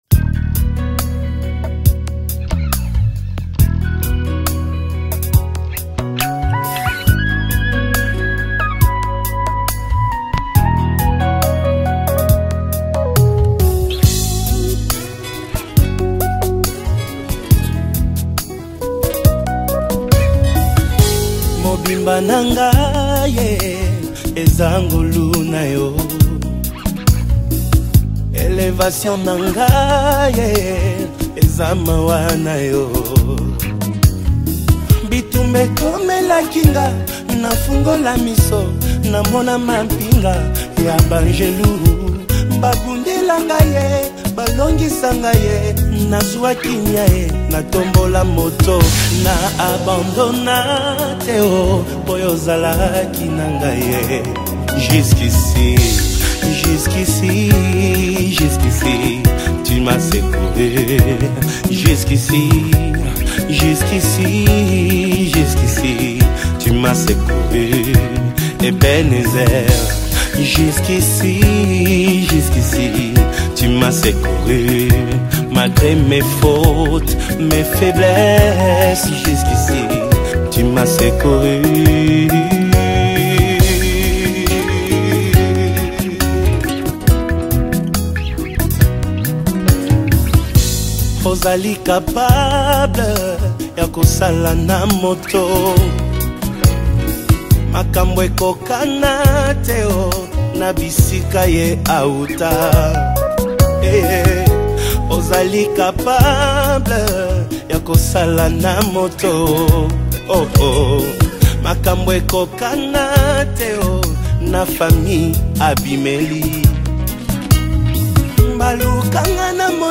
PROPHETIC WORSHIP ANTHEM